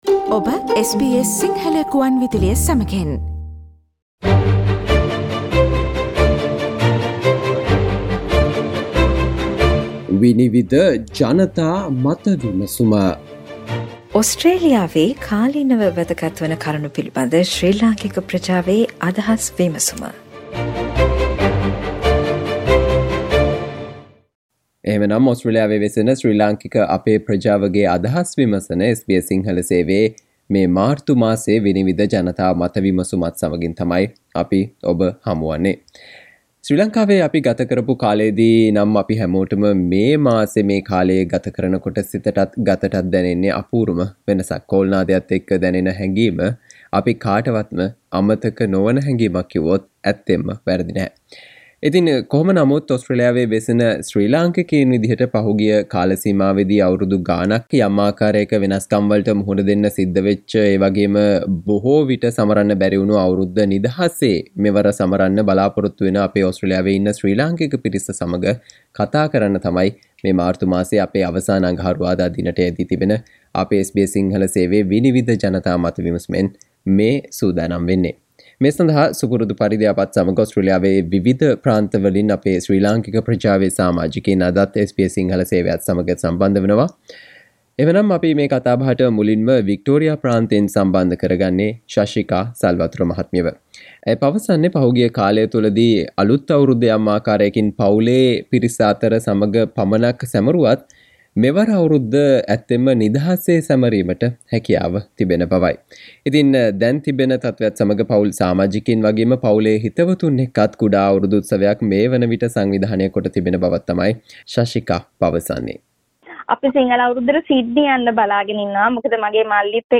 වසර කිහිපයකට පසු නැවතත් නිදහසේ සිංහල දමිළ අලුත් අවුරුද්ද සැමරීමට වන සුදානම ගැන ඕස්ට්‍රේලියාවේ වෙසෙන ශ්‍රී ලංකික ප්‍රජාව දැක්වූ අදහස් වලට සවන් දෙන්න මෙම මාර්තු මාසයේ SBS සිංහල ගුවන් විදුලියේ 'විනිවිද' ජනතා මත විමසුම තුලින්